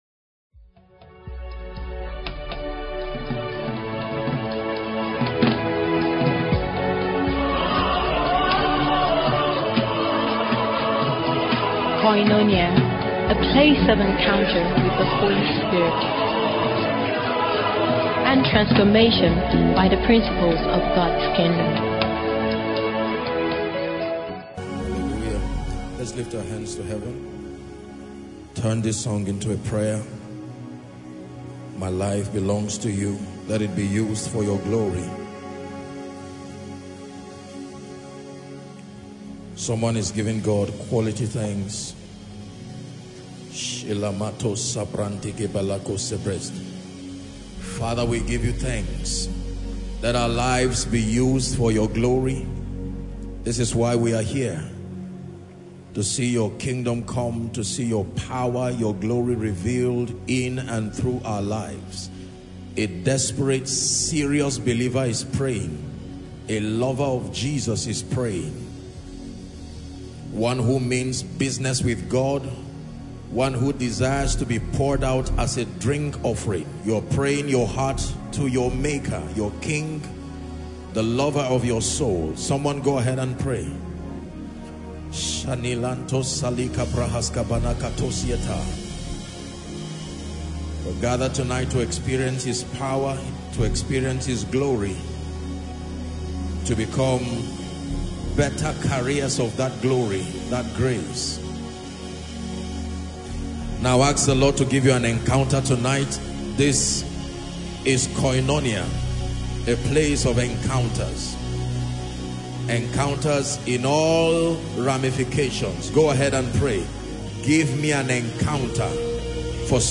This sermon reminds us that no season whether painful or pleasant is permanent. God operates through times and seasons, and His dealings with men are intentional, redemptive, and purposeful.